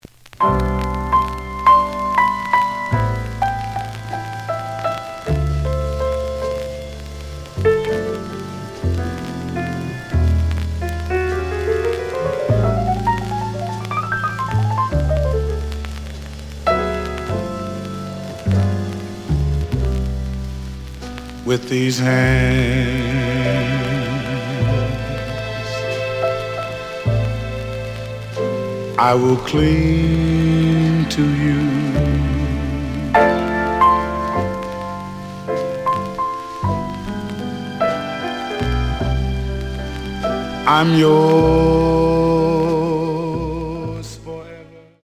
The audio sample is recorded from the actual item.
●Genre: Jazz Funk / Soul Jazz
B side plays good.)